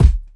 Raw Kickdrum Sound C# Key 02.wav
Royality free kick drum one shot tuned to the C# note. Loudest frequency: 161Hz
.WAV .MP3 .OGG 0:00 / 0:01 Type Wav Duration 0:01 Size 30,5 KB Samplerate 44100 Hz Bitdepth 16 Channels Mono Royality free kick drum one shot tuned to the C# note.
raw-kickdrum-sound-c-sharp-key-02-lM4.ogg